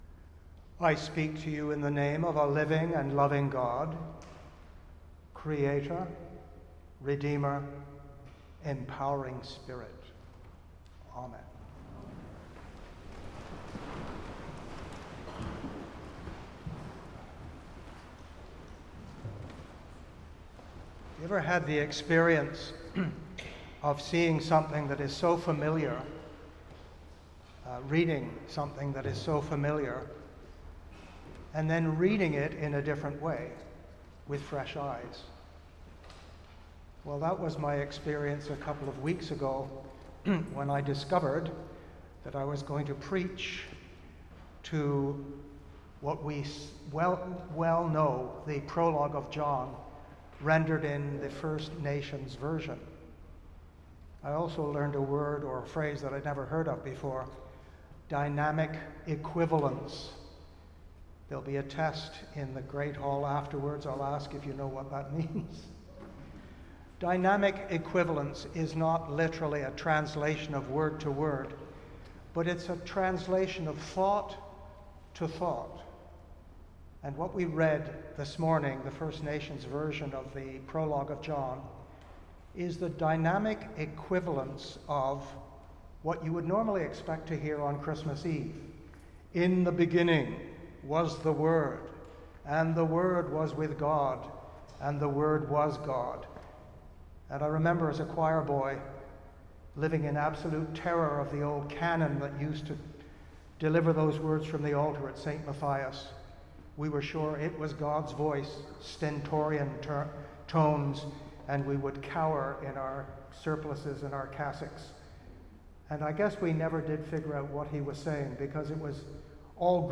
Sermons | Christ Church Cathedral Ottawa